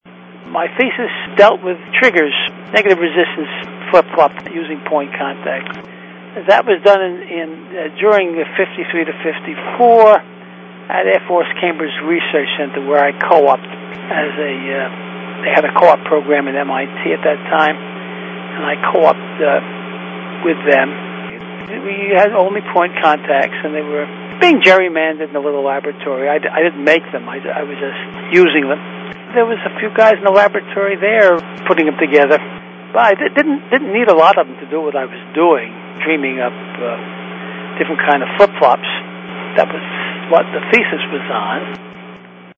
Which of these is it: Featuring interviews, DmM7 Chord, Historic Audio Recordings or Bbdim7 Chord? Historic Audio Recordings